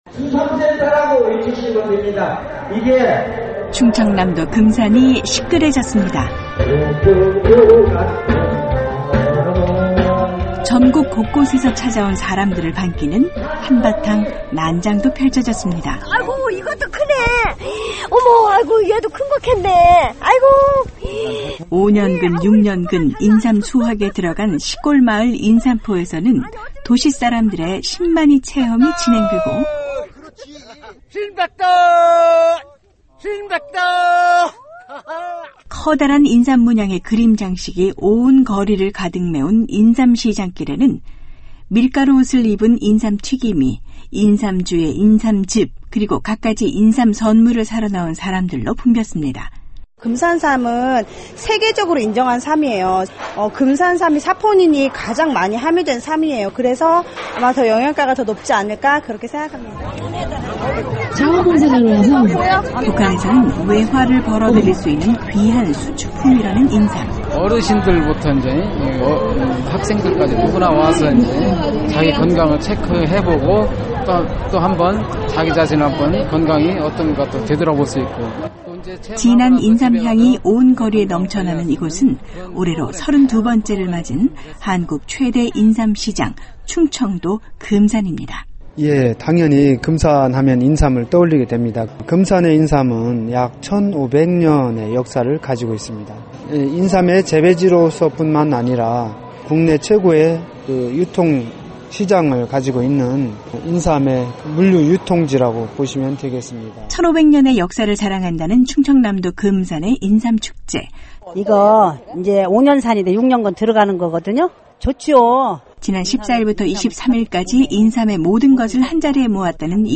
지난주 한국에서 가장 큰 인삼시장인 충청남도 금산에서 ‘인삼’의 모든 것을 선보이는 축제가 열렸다고 합니다. ‘안녕하세요. 서울입니다’ 오늘은 인삼축제 현장으로 가 보겠습니다.